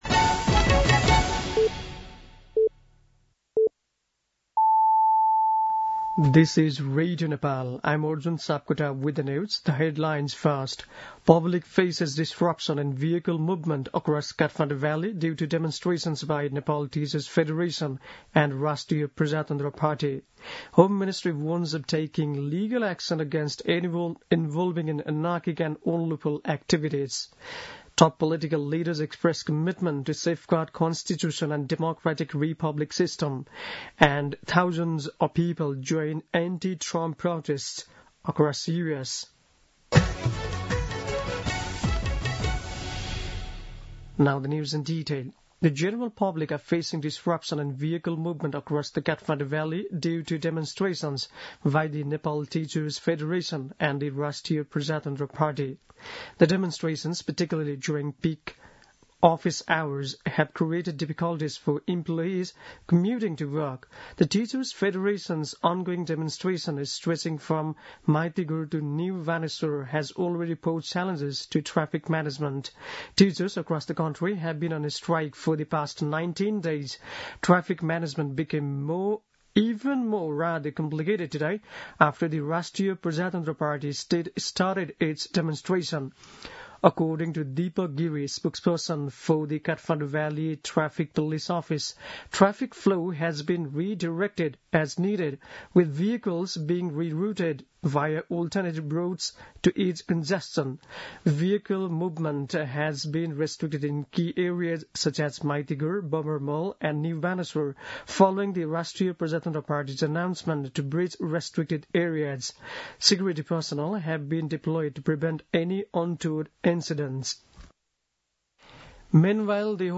An online outlet of Nepal's national radio broadcaster
दिउँसो २ बजेको अङ्ग्रेजी समाचार : ७ वैशाख , २०८२
2-pm-English-News-01-07.mp3